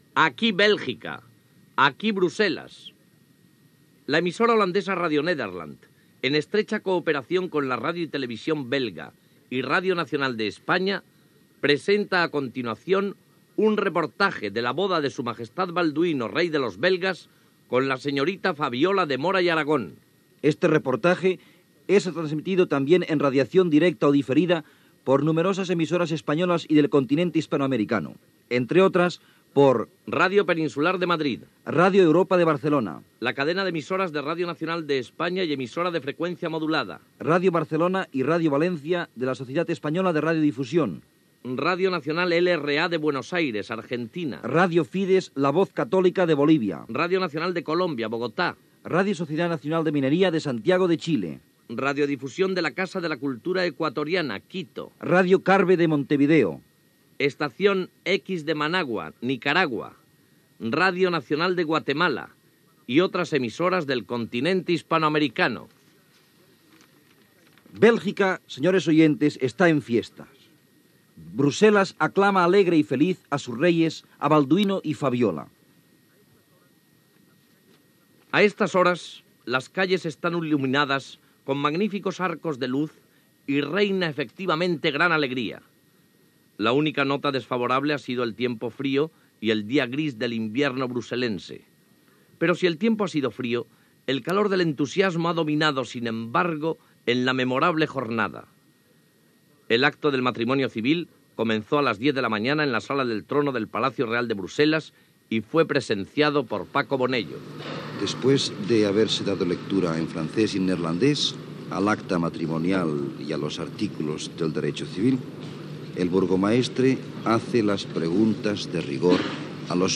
Reportatge de Radio Nederland, RTV Belga i RNE del casament del rei Balduí de Bèlgica amb Fabiola de Mora y Aragón.
Informatiu